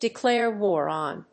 アクセントdecláre wár on [upòn, agàinst]…